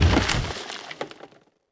car-wood-impact-04.ogg